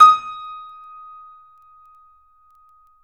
Index of /90_sSampleCDs/E-MU Producer Series Vol. 5 – 3-D Audio Collection/3DSprints/3DYamahaPianoHyb